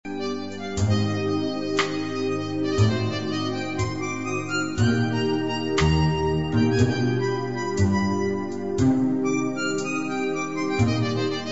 demos are 10sec .mp3 mono 32Kbit/s